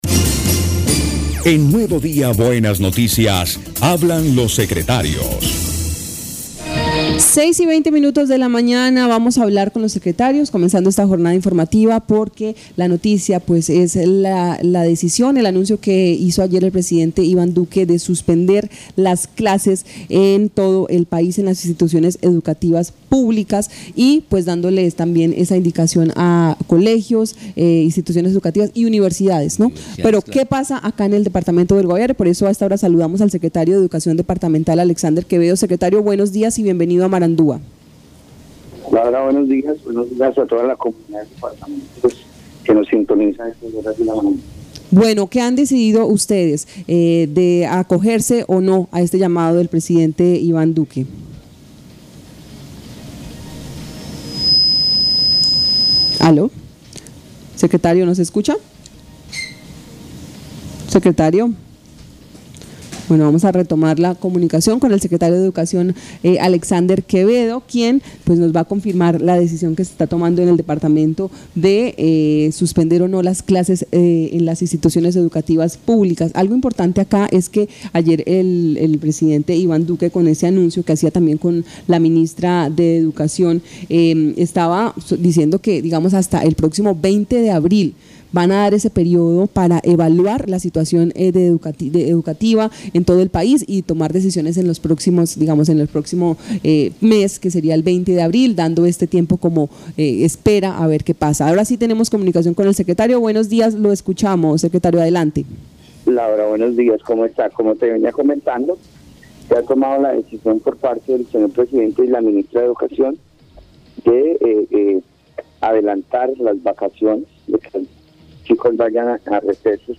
Escuche a Alexander Quevedo, secretario de Educación del Guaviare.